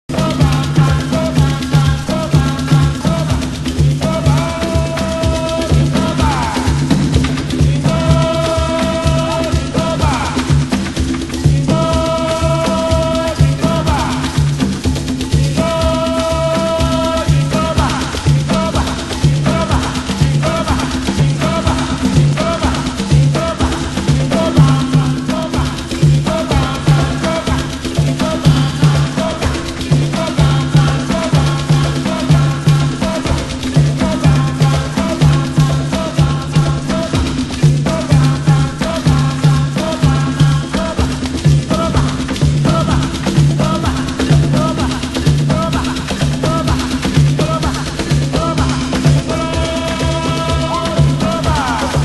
○AFRO/LATIN/TRIBAL/DISCO...
盤質：少しチリノイズ有